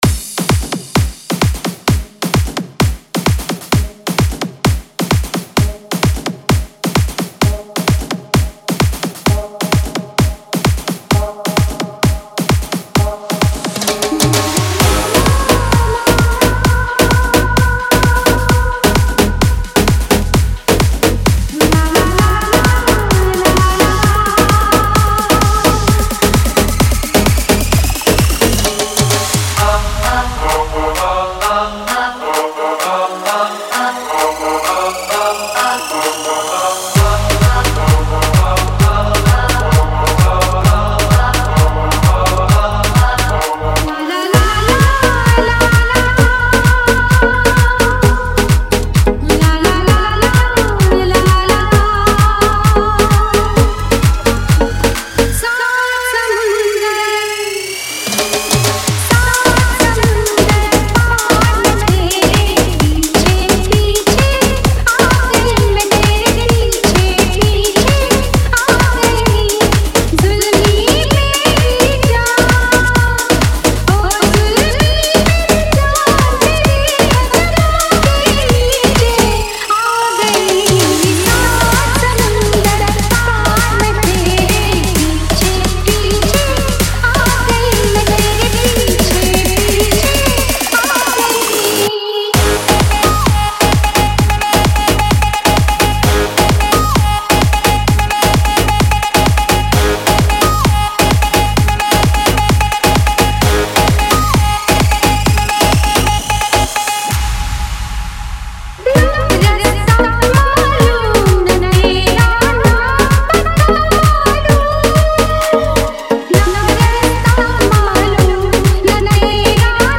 wedding remix